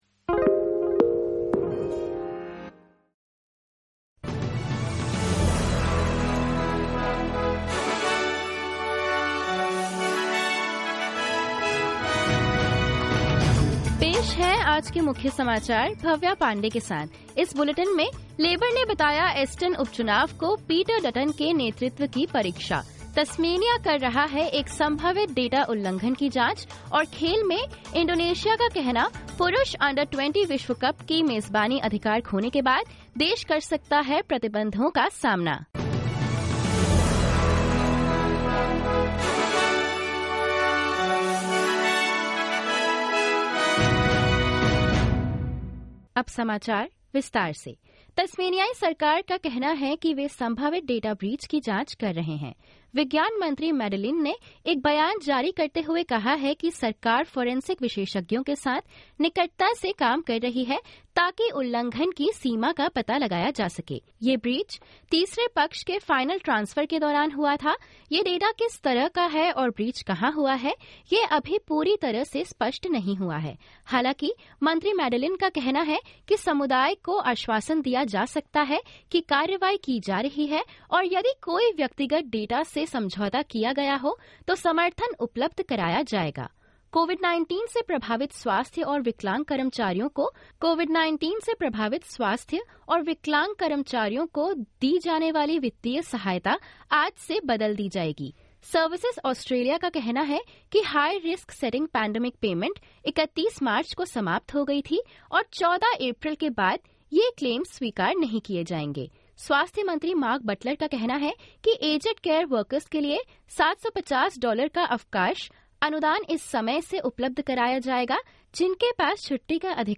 In this latest Hindi bulletin: Aston byelection to be a leadership test for Liberal party leader Peter Dutton; Tasmanian State government data potentially lost in a cyber breach; FIFA takes back Indonesia' U20 World Cup hosting rights over Israel controversy and more.